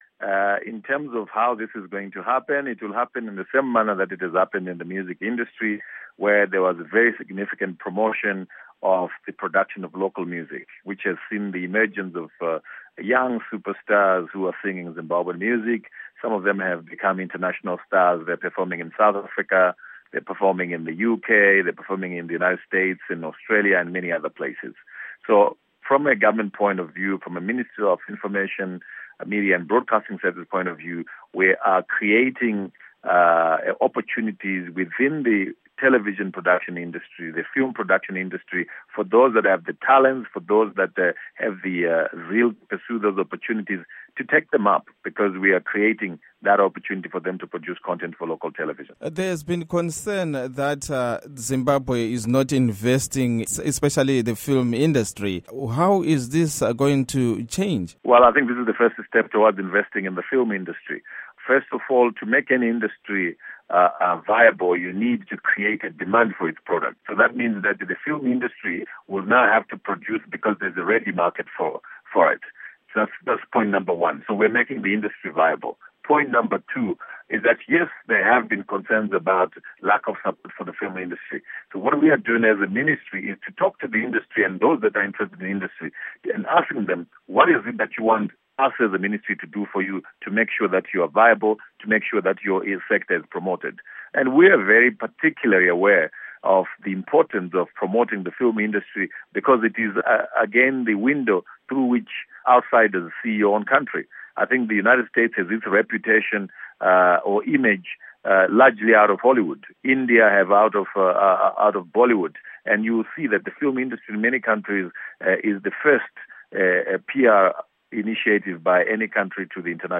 Interview With Supa Mandiwanzira